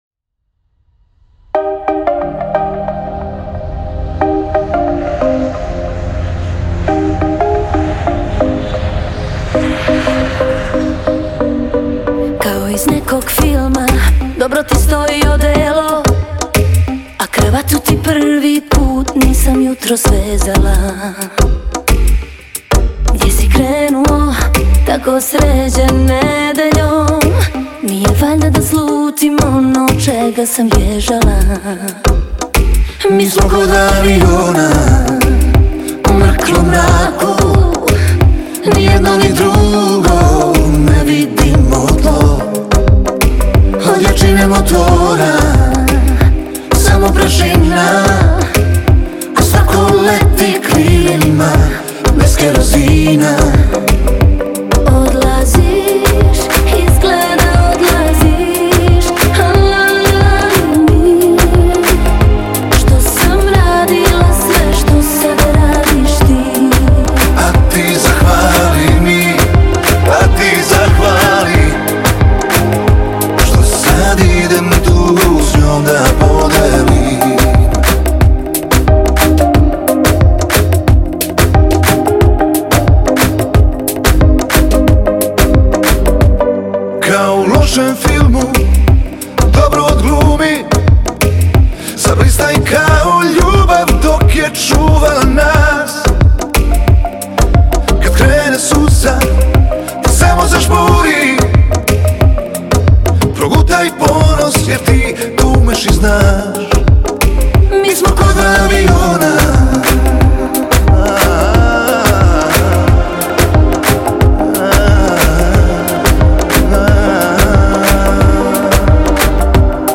это романтическая баллада в жанре поп